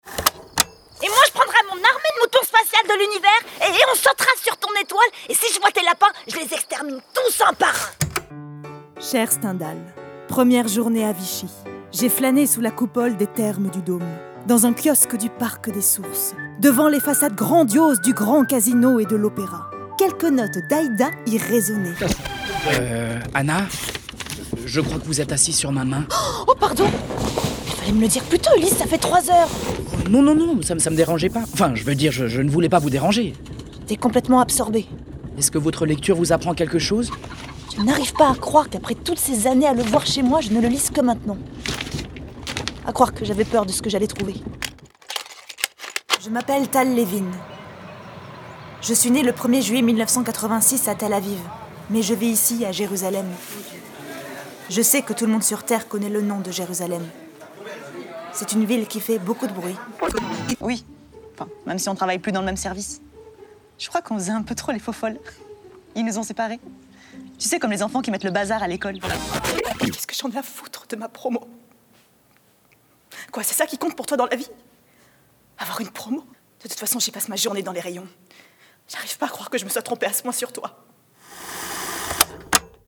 Voix off
Démo voix
25 - 35 ans - Soprano